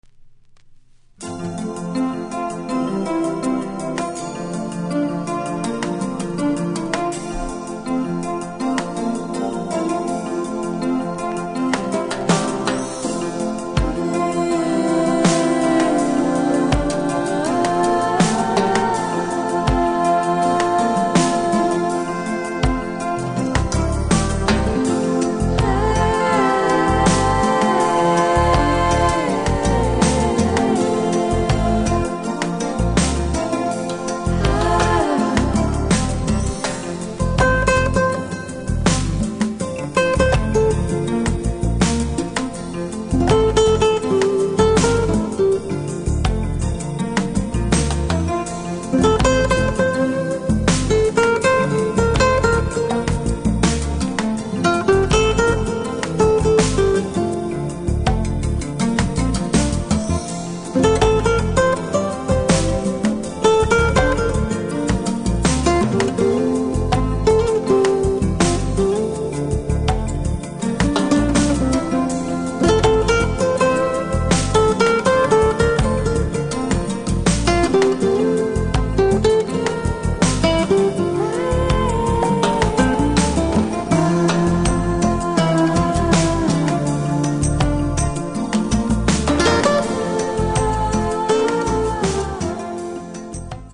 (Instrumental)
このギターのインストもお勧めです。